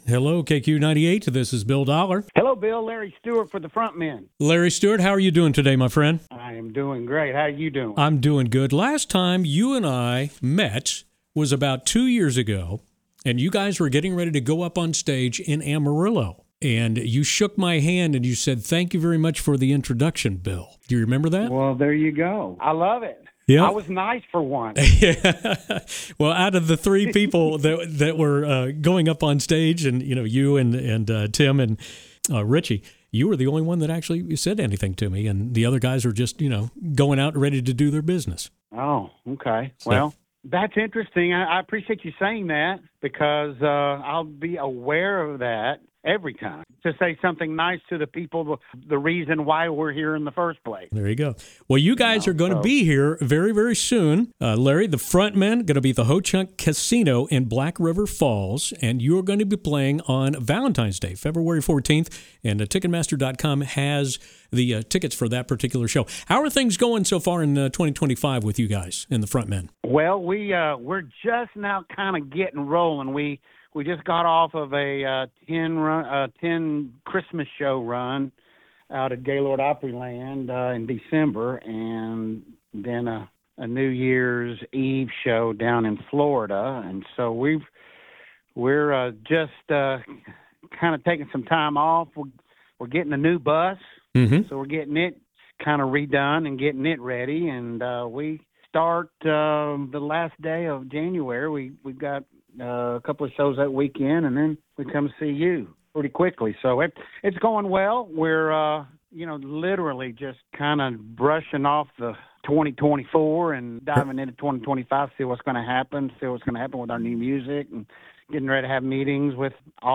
LARRY-STEWART-INTERVIEW-MP3-FILE.mp3